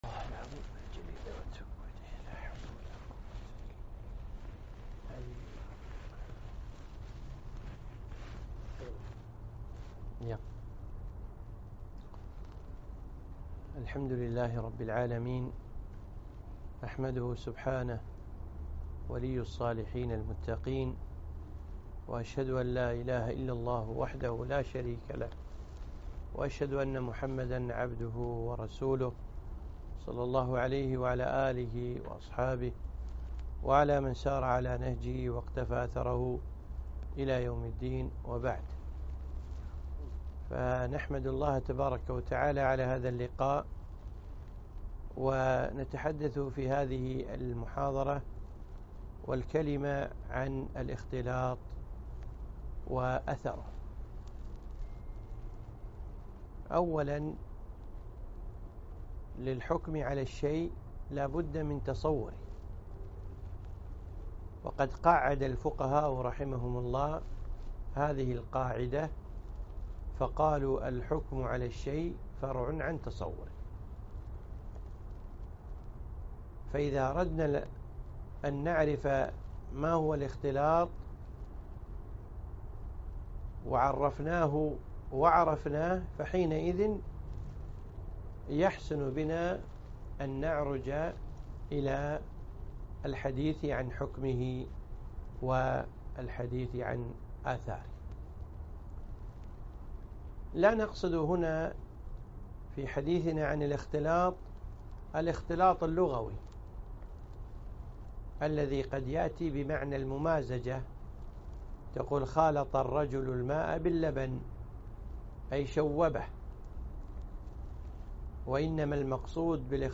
محاضرة - الإختلاط وأثره